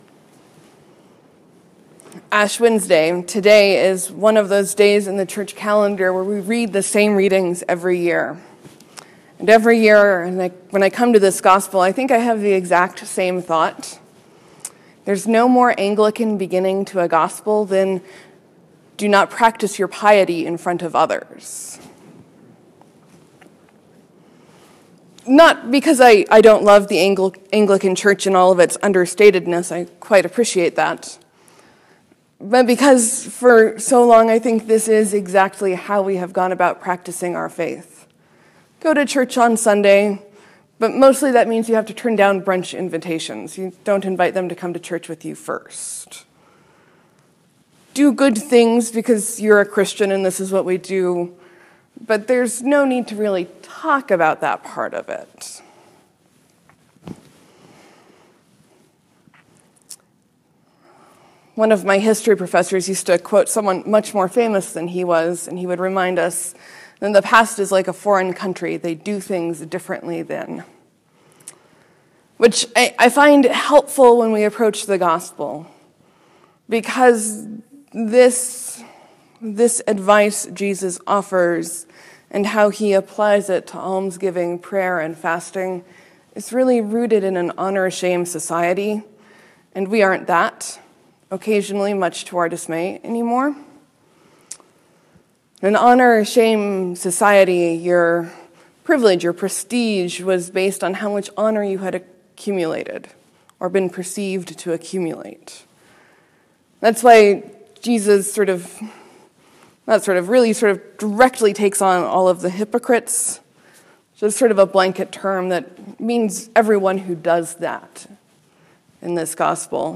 Lent, Sermon, , , , , , Leave a comment